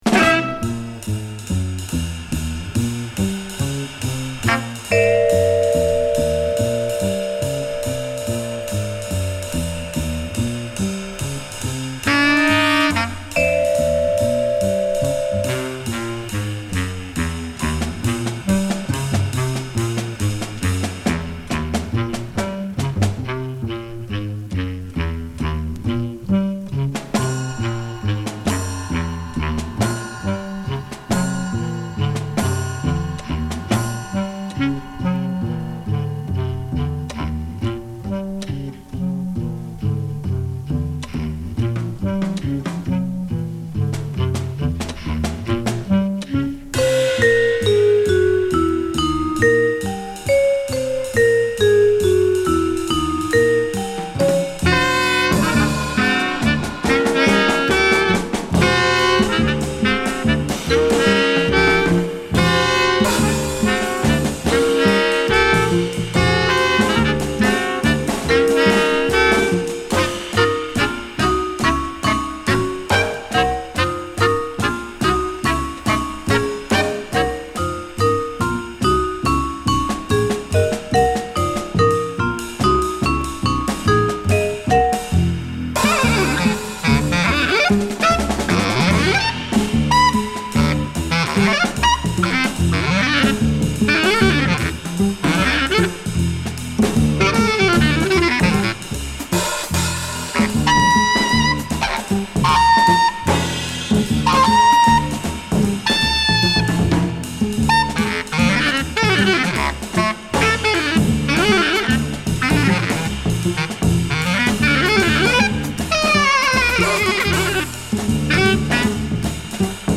サックス／フルート／クラリネット奏者
複雑でテクニカルなフリー〜バップを収録！